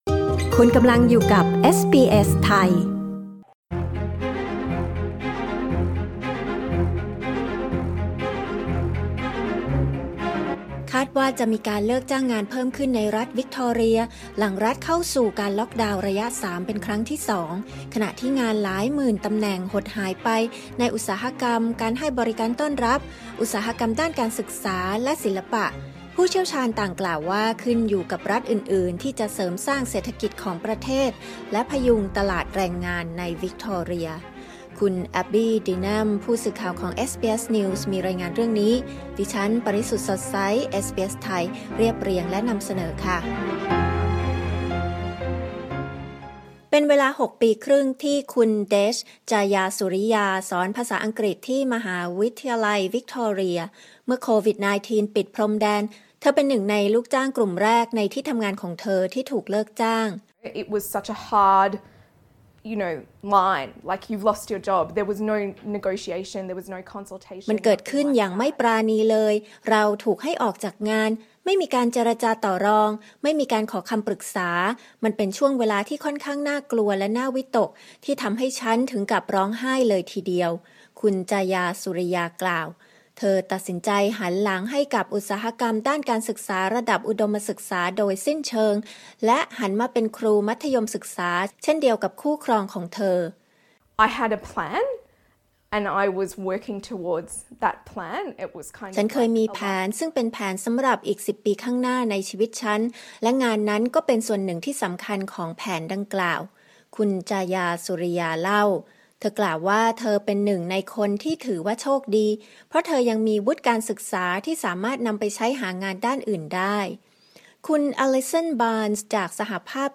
กดปุ่ม 🔊ที่ภาพด้านบนเพื่อฟังรายงานข่าว